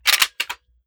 7Mag Bolt Action Rifle - Slide Forward-Down 001.wav